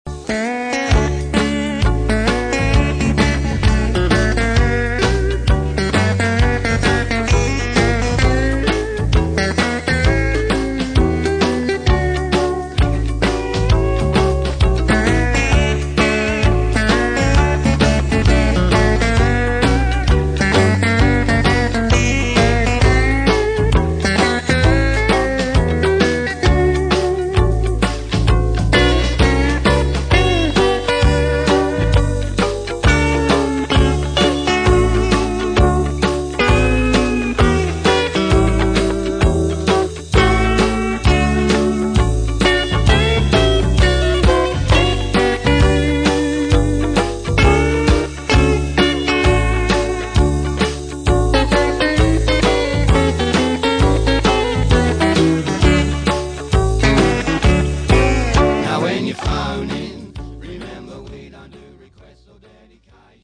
radio jingle talk-over track.
that.solo.mp3